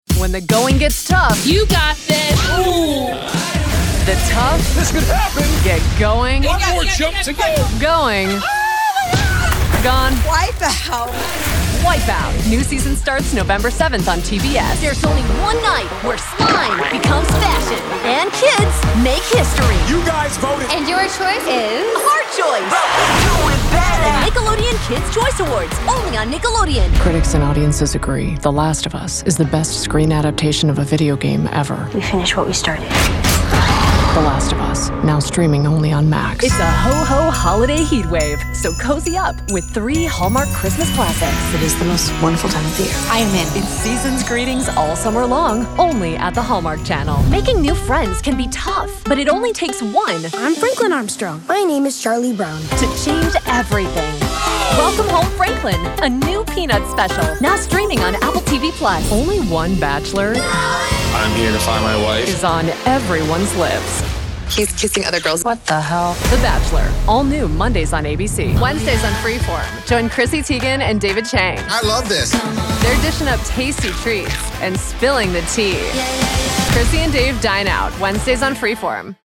Sennheiser MKH 416, Neumann U87, Apollo Twin X
Cabina personalizada diseñada profesionalmente con nivel de ruido de -84 dB, techo y piso desacoplados, sin paredes paralelas